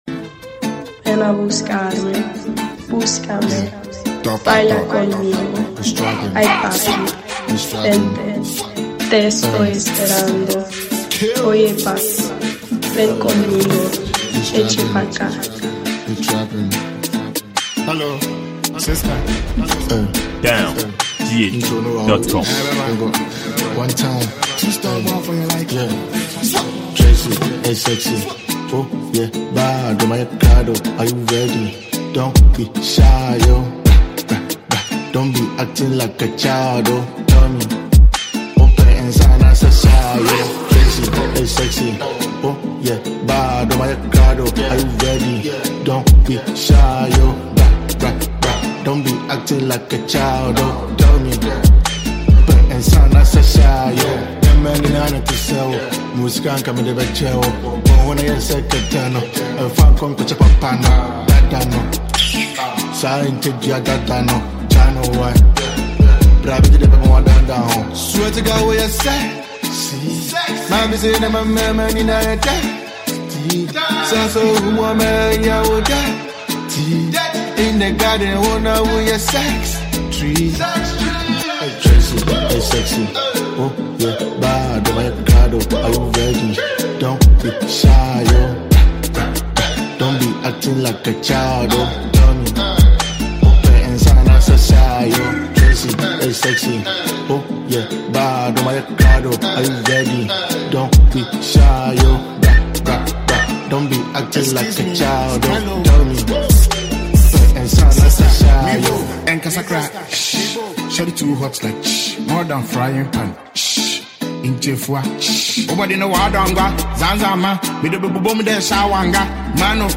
Ghana Music
rapper